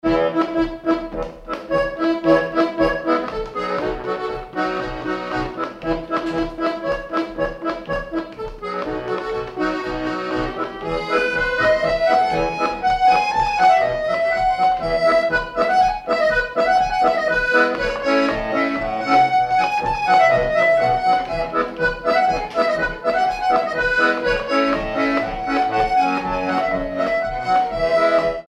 Danse
circonstance : bal, dancerie
Pièce musicale inédite